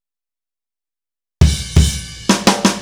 Indie Pop Beat Intro 01.wav